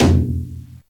taiko-soft-hitnormal.ogg